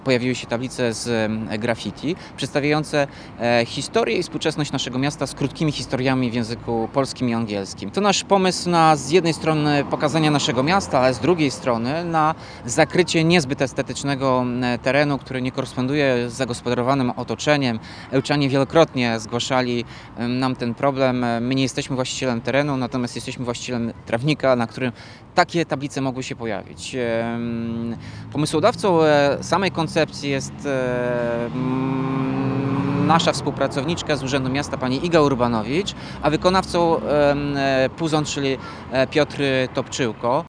mówi Tomasz Andrukiewicz, prezydent Ełku.